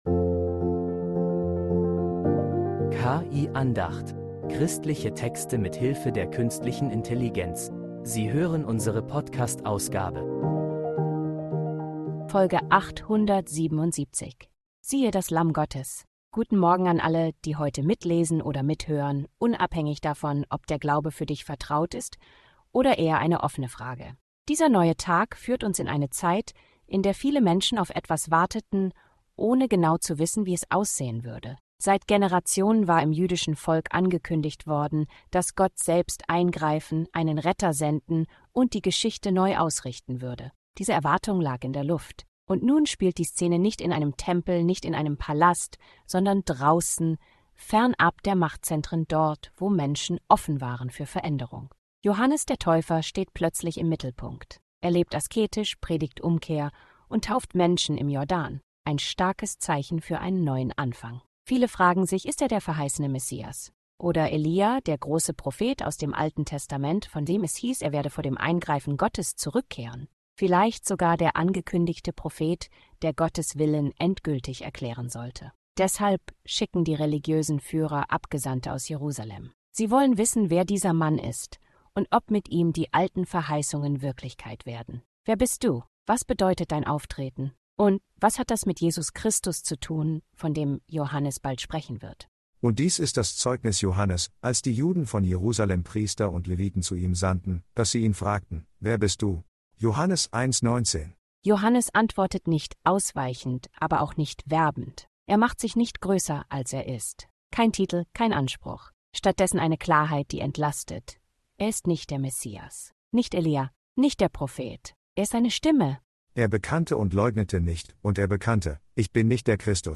Eine Andacht über klare Worte, offenen Blick und einen, der Lasten trägt